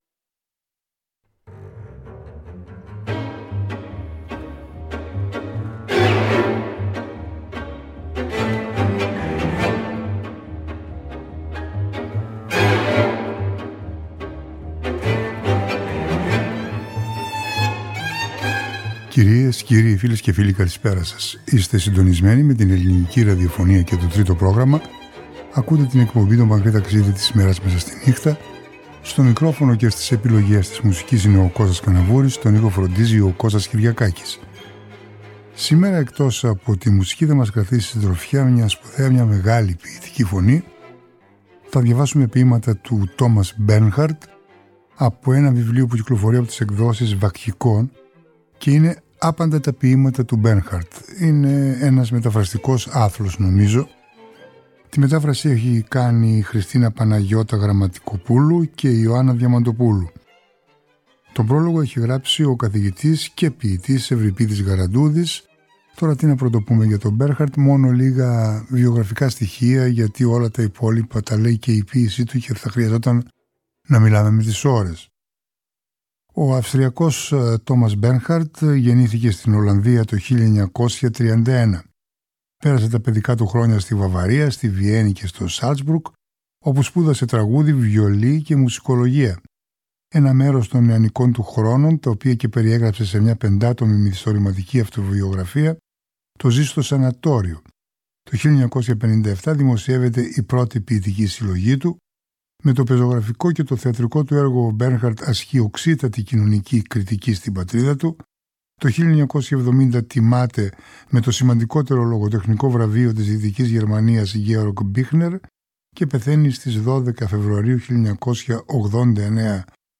Την εκπομπή πλαισιώνουν εξαίσεις μουσικές των: Rossini, Rachmaninoff, Mozart, Puccini, Bach, Saint – Sans, Drovak, Glazunov, Tchaikovsky, Haydn.